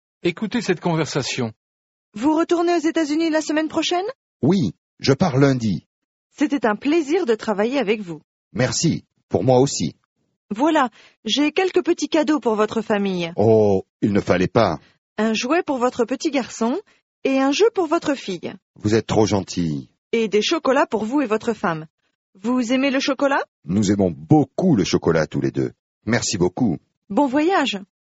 Аудио курс для самостоятельного изучения французского языка.